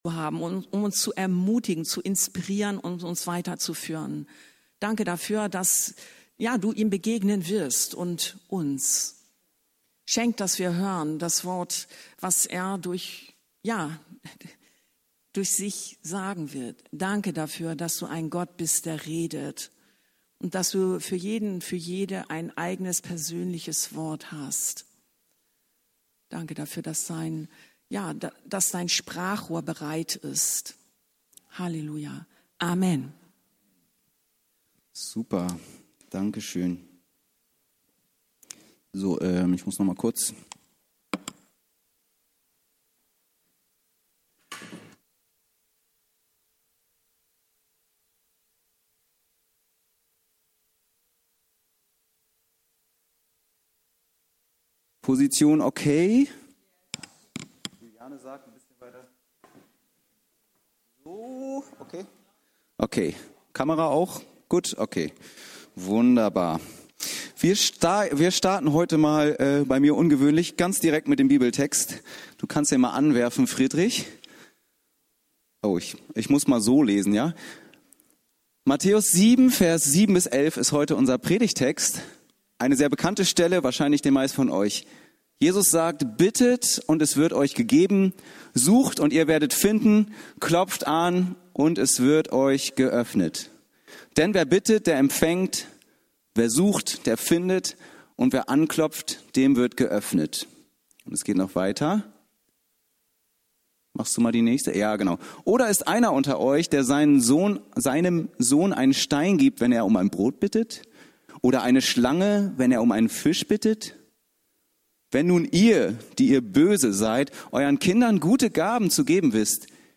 Bei genauerem Hinsehen merken wir, dass es doch einige Facetten gibt, wenn es um die Erhörung unserer Gebete geht. Eine davon wollen wir in dieser Predigt unter die Lupe nehmen.